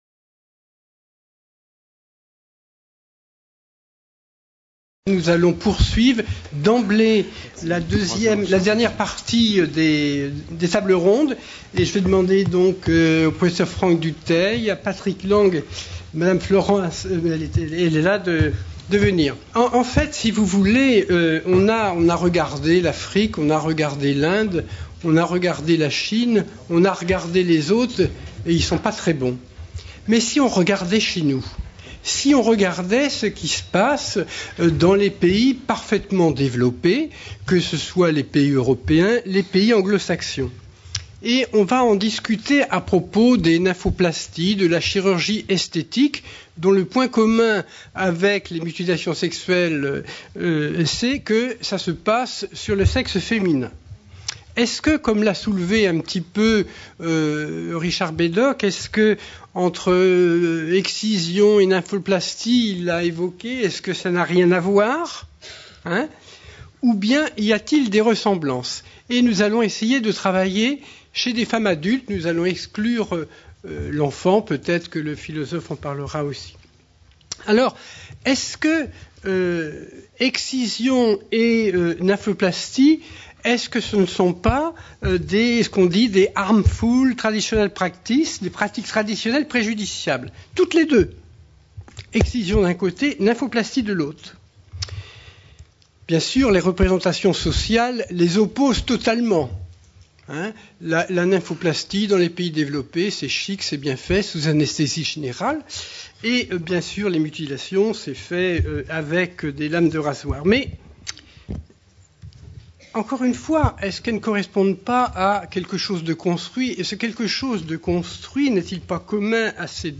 4ème Journée Humanitaire sur la Santé des Femmes, organisée par Gynécologie Sans Frontières, le 29 novembre 2013, au Palais du Luxembourg (Paris). De la culture traditionnelle à la mode, quand la chirurgie devient une autre forme de violence faite aux femmes.